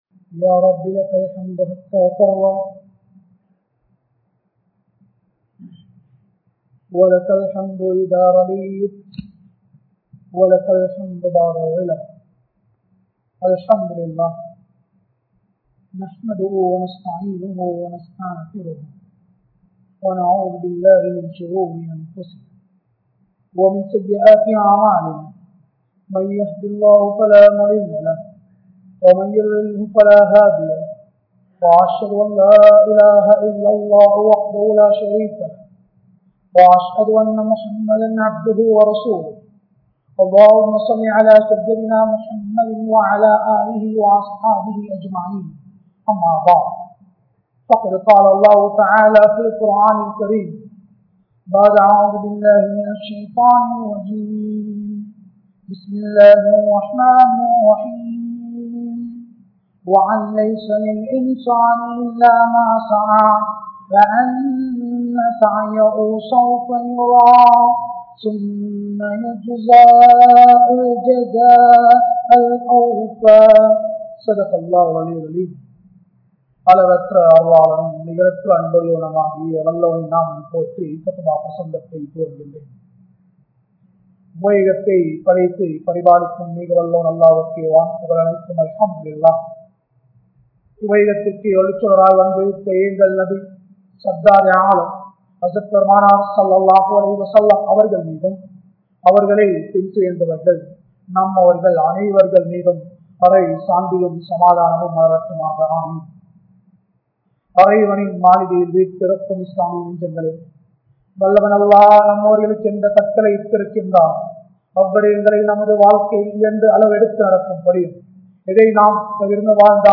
Ilangaiel Muslimkalin Kalvien Avasiyam (இலங்கையில் முஸ்லிம்களின் கல்வியின் அவசியம்) | Audio Bayans | All Ceylon Muslim Youth Community | Addalaichenai